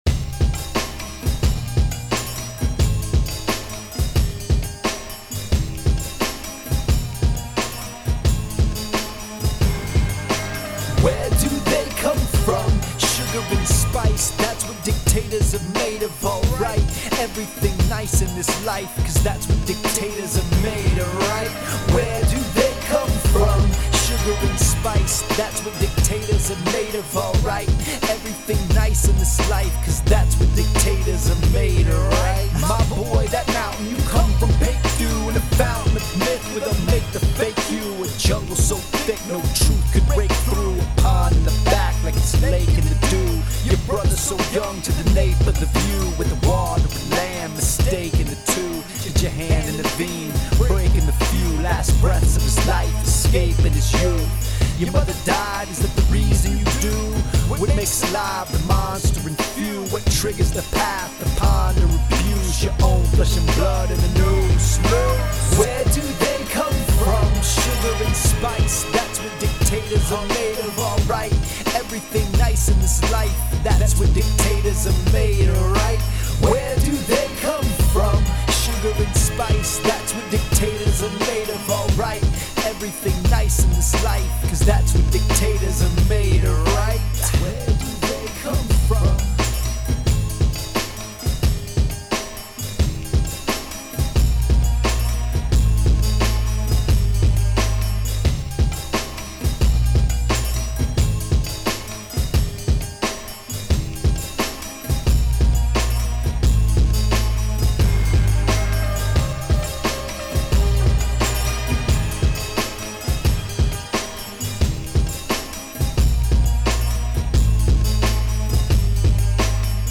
This song is not catchy or for kids. 2 obituary songs in a week’s time right before the holidays.
Thumbs Up – Good beat matched with nice vocals.